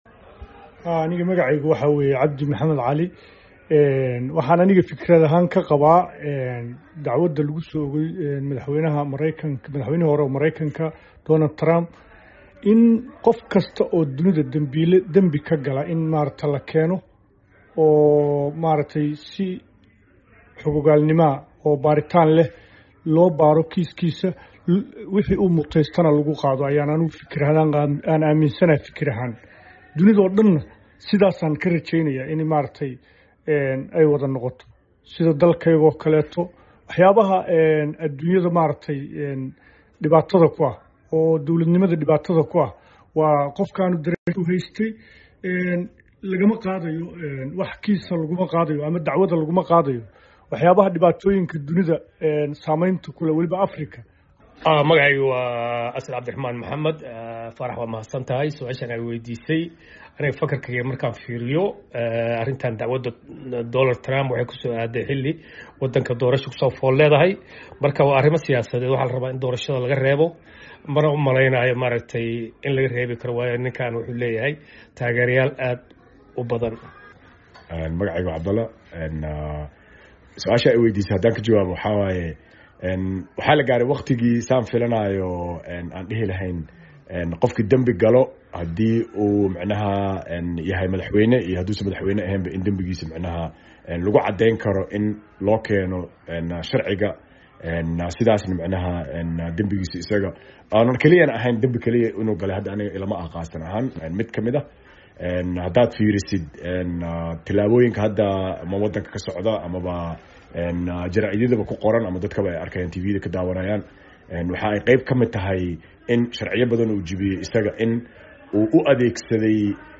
vox pop-2.m4a